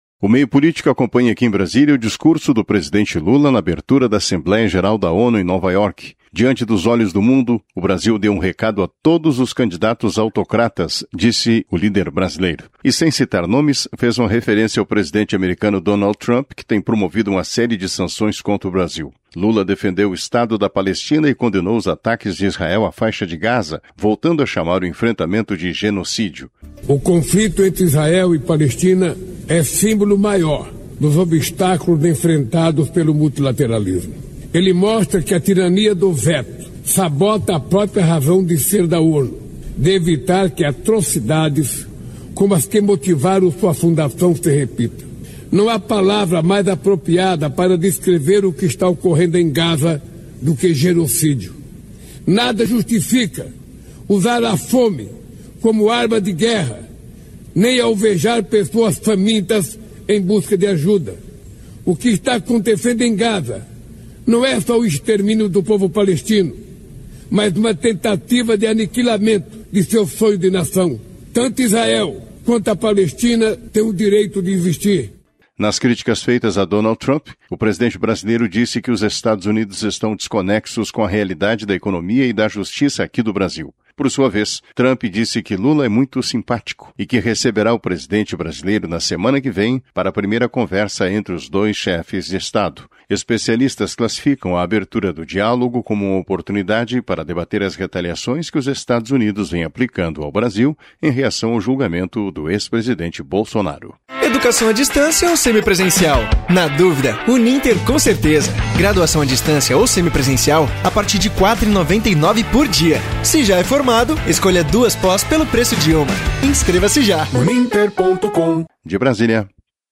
Lula discursa na ONU sobre matança na Faixa de Gaza e sobre sanções americanas ao Brasil